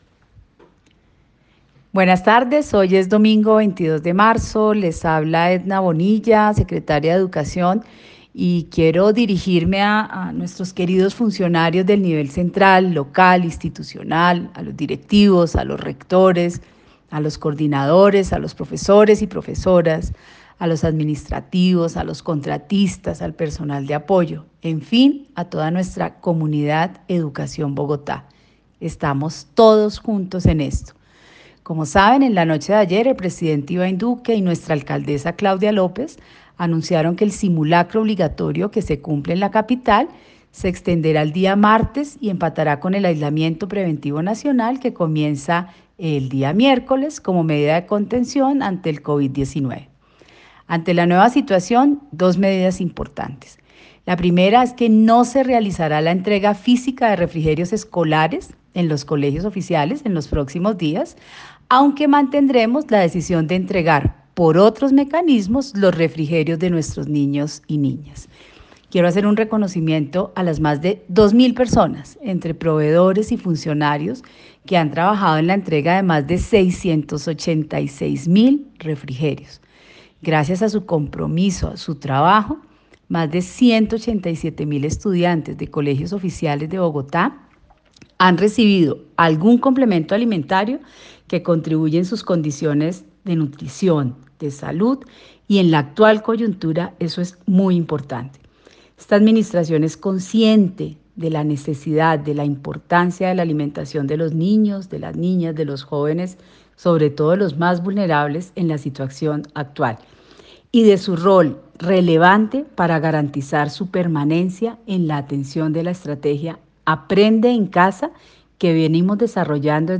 Por parte de la señora secretaria de Educación del Distrito, Edna Bonilla Sebá: